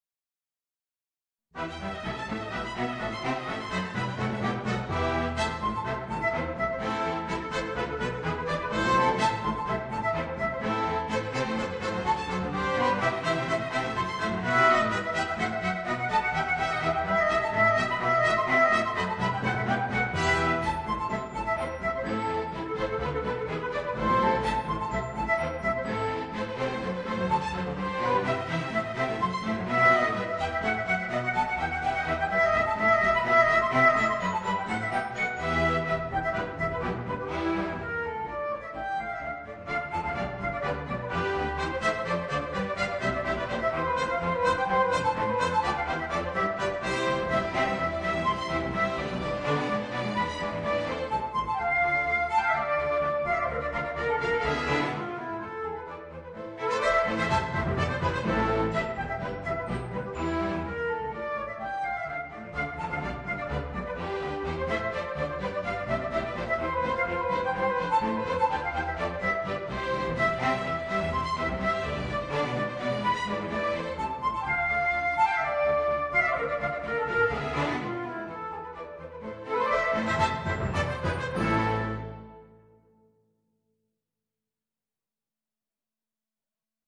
Voicing: Bassoon and Orchestra